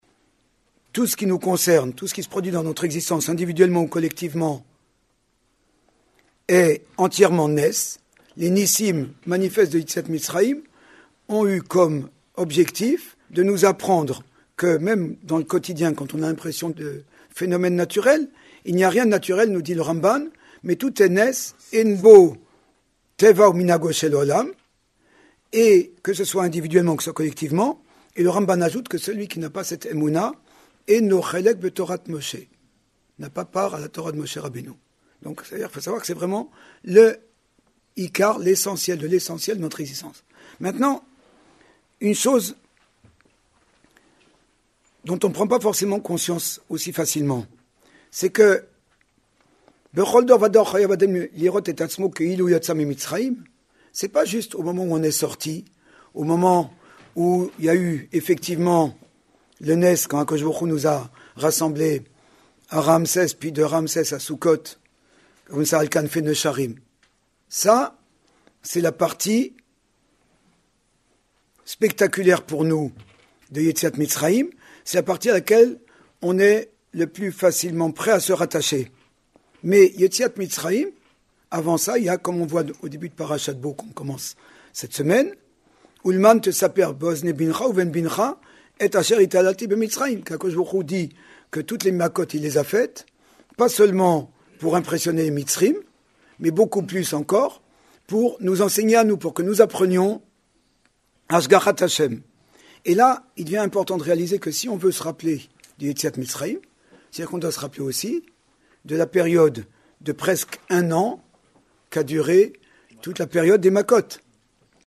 00:53:26 Nous étions le 13 janvier 2018 dans les locaux de Toraténou à Paris.